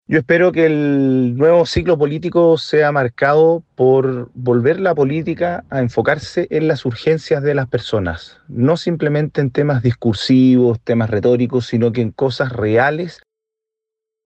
En esa línea, el diputado republicano Benjamín Moreno expresó que espera, con el inicio del gobierno de Kast, que la urgencia esté en dar respuestas concretas a las demandas de la ciudadanía.